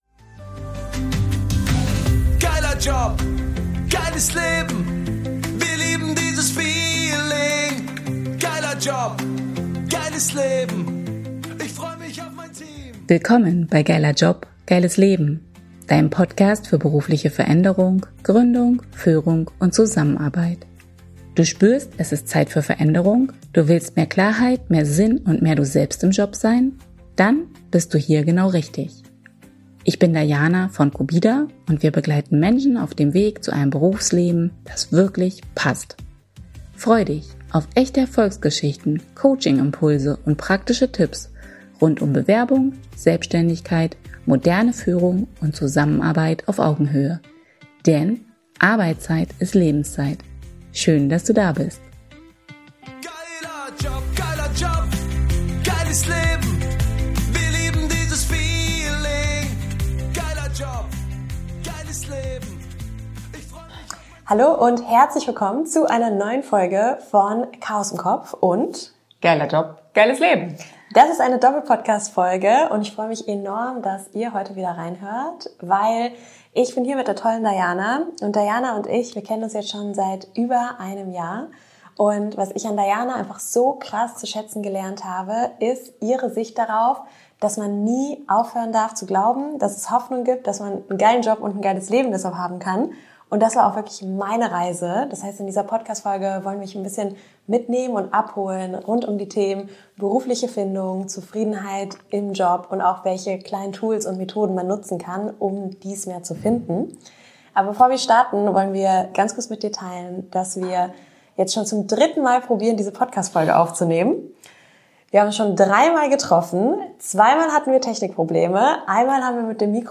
#017 Chaos im Kopf: Wenn der Job nicht passt | Interview